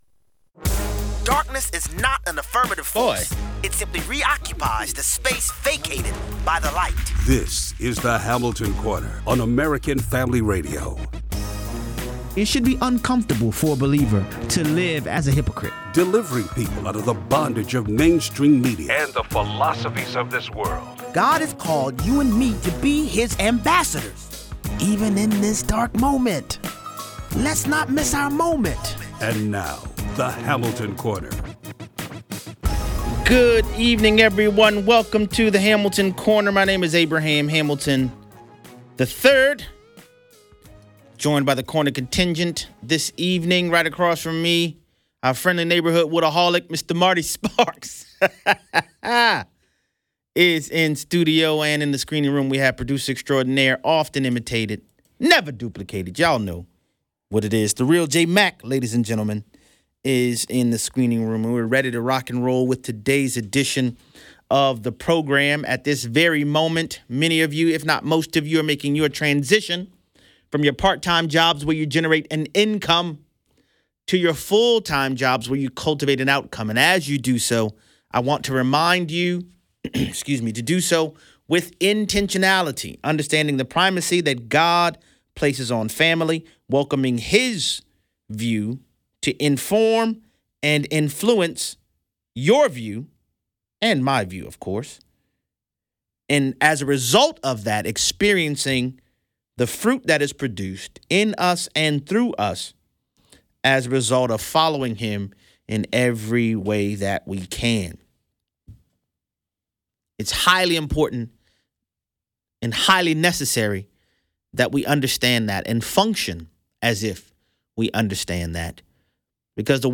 Guest Host, Bishop E.W. Jackson, talks about the love and hatred towards President Trump. Guest Host, Bishop E.W. Jackson, discusses Halloween and what’s happening with ICE in Chicago.